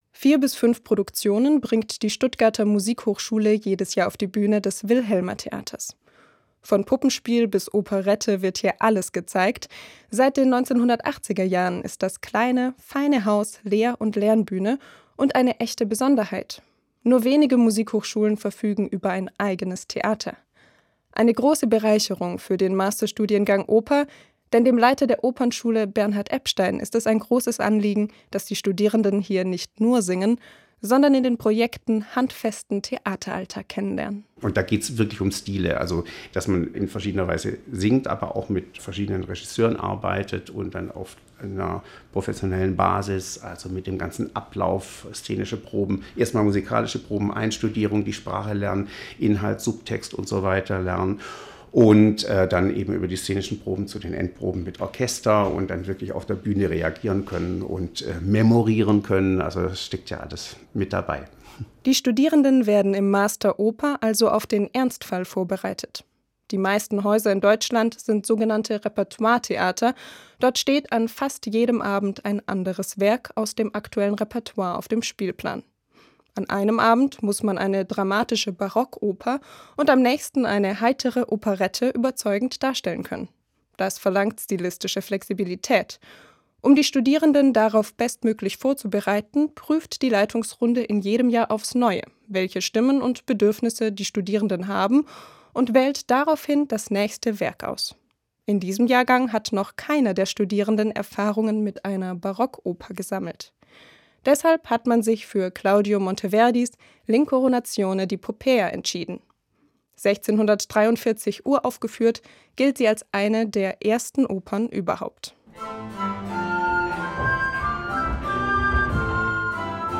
Musikthema